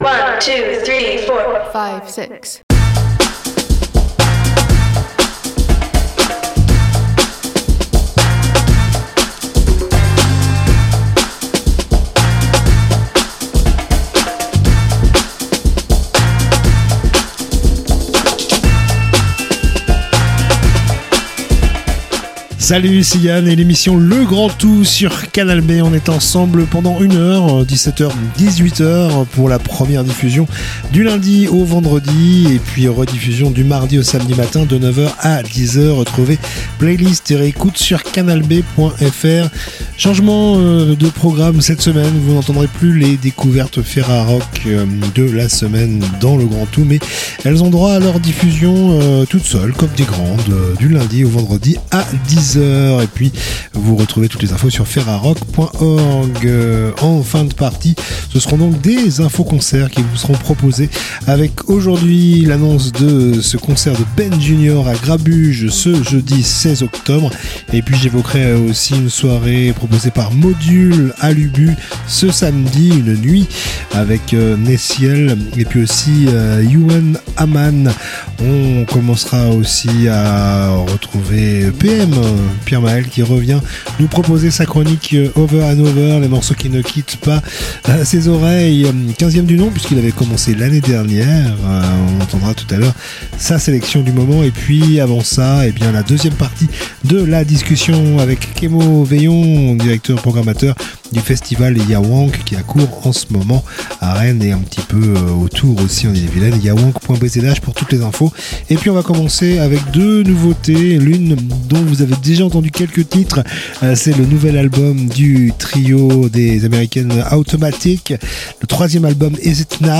itv musique & concerts